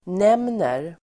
Uttal: [n'em:ner]